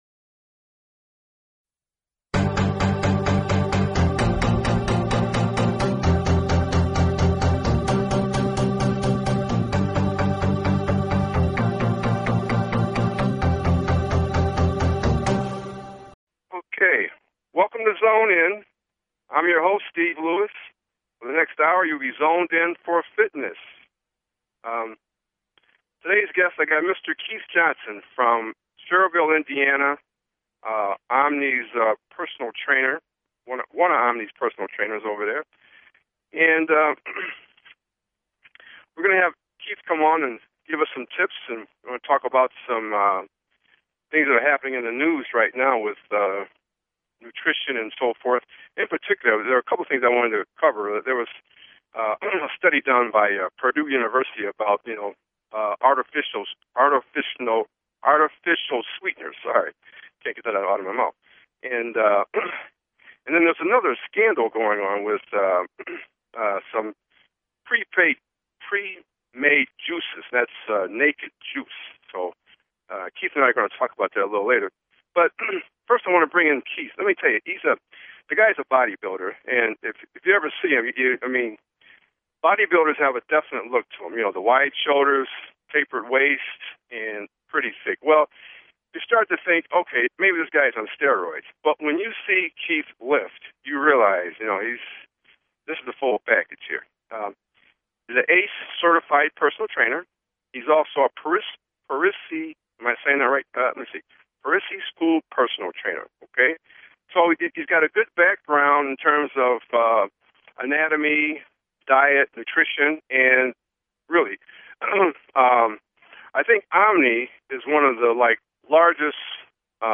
Talk Show Episode, Audio Podcast, Zone In!